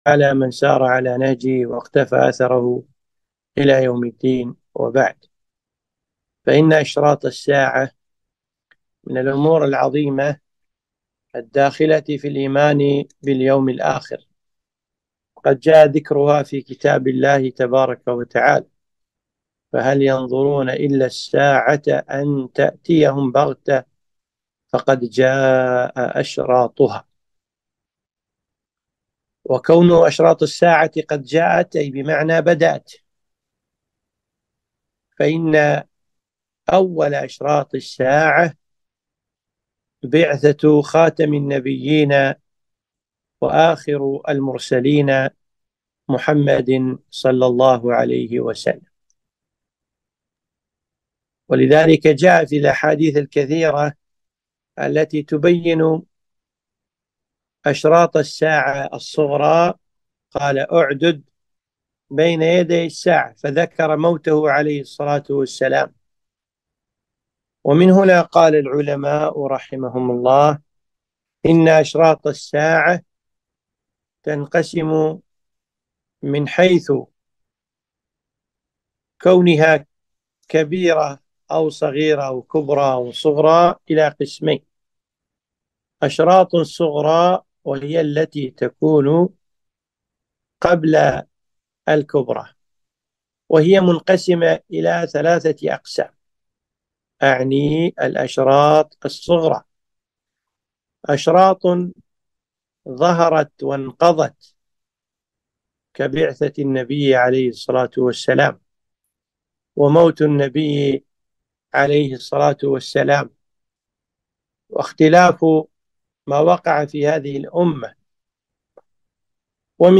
محاضرة - أشراط الساعة